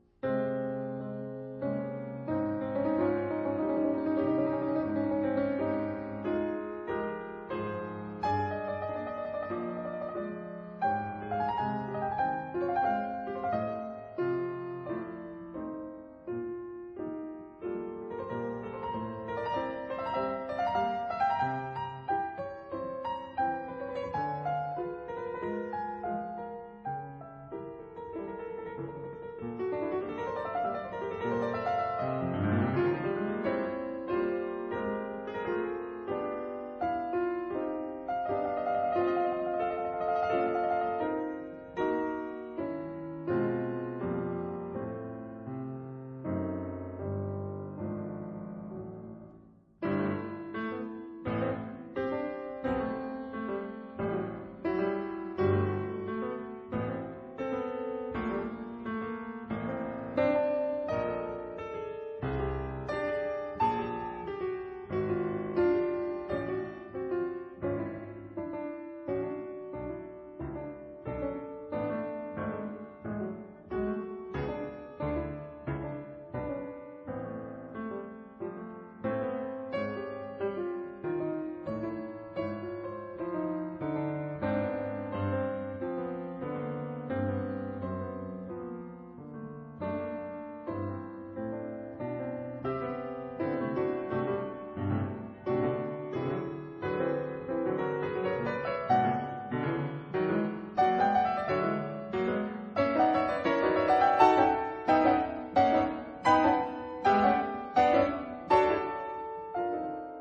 試聽二是未曾出版，改編的爵士作品。